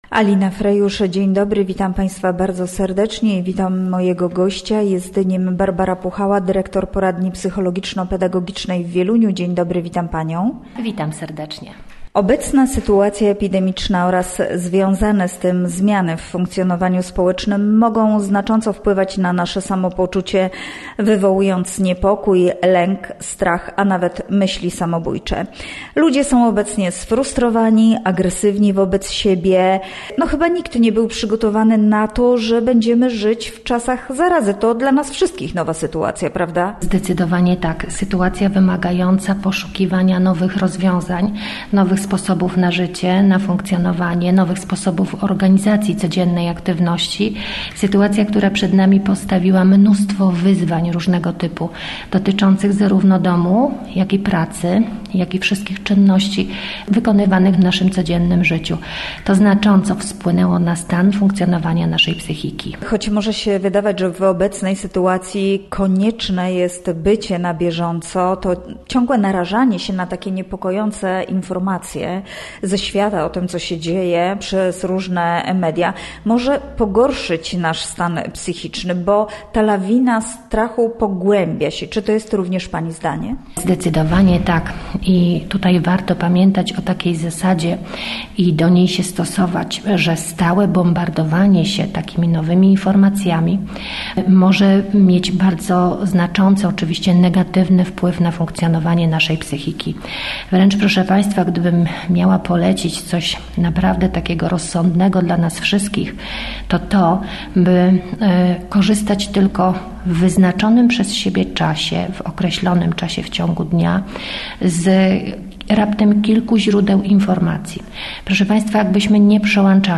Pogarsza się stan zdrowia psychicznego społeczeństwa. W obecnej sytuacji związane jest to z epidemią, strachem przed chorobą, izolacją, samotnością. W rozmowie z naszym gościem pytamy, co zrobić, aby poprawić sobie samopoczucie i nie dać się jesiennej chandrze.